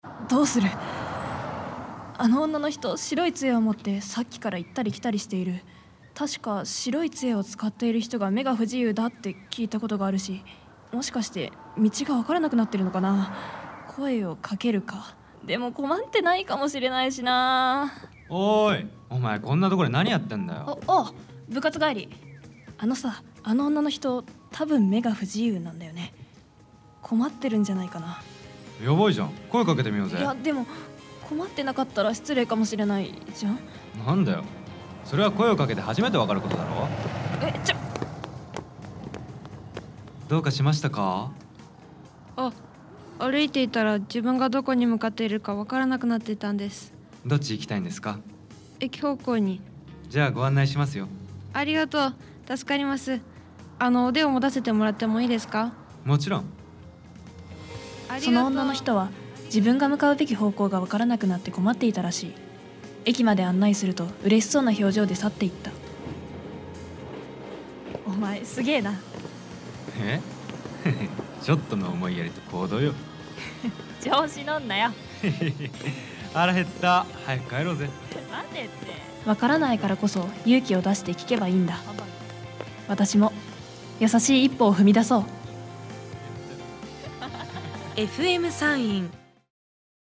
ラジオCM
今、話題のあおり運転のことを言っているのかなと思ったら見事に裏切られましたね。これぞ、ラジオ広告。